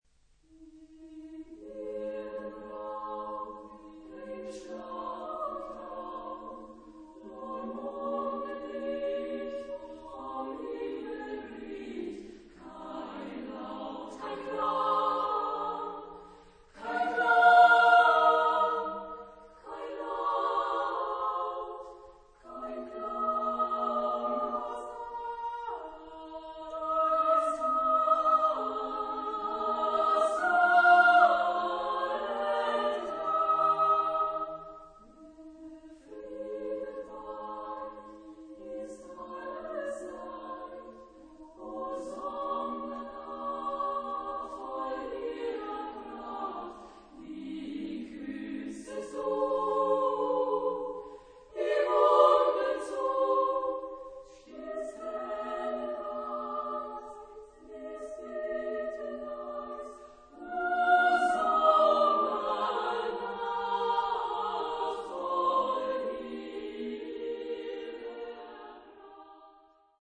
Genre-Style-Form: Choir ; Poetical song ; Romantic ; Secular
Mood of the piece: adagio ; expressive ; calm
Type of Choir: SSAA  (4 women voices )
Tonality: A flat major